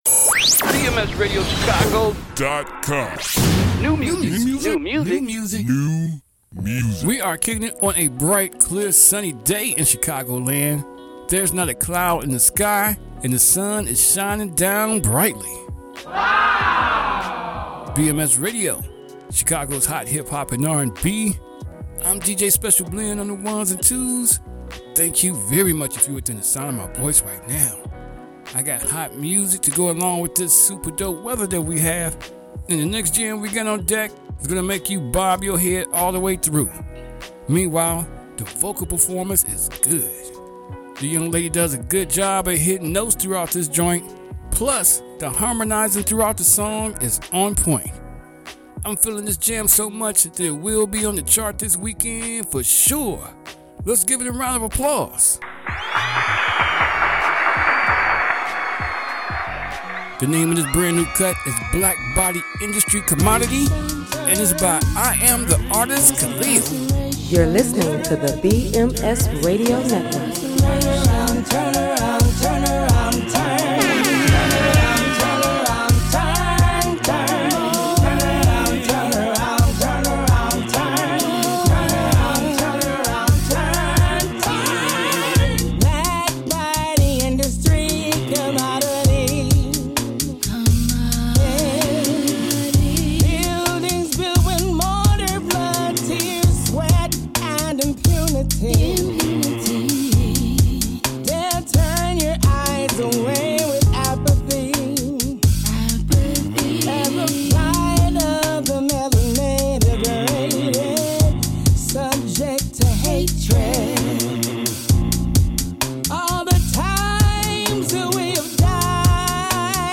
The vocal performance on this cut is good.